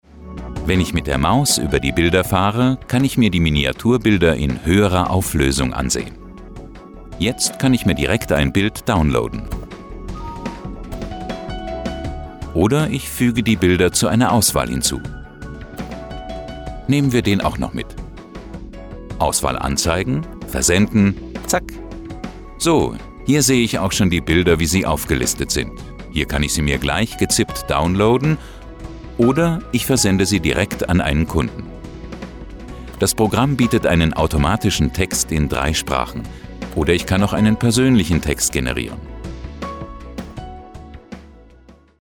Deutscher Sprecher.
Sprechprobe: eLearning (Muttersprache):
german voice over artist